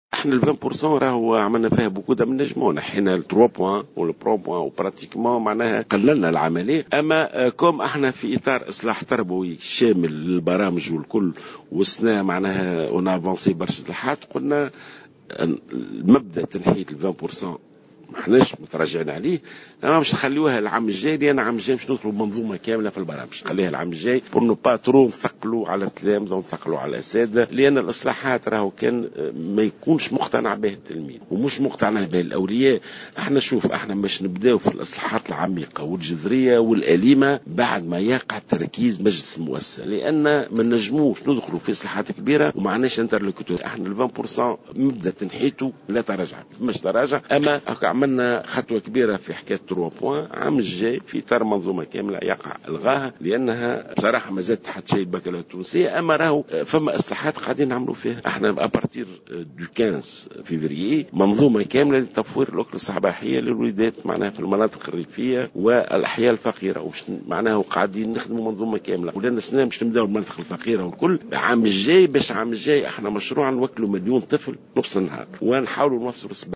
أعلن وزير التربية ناجي جلول في تصريح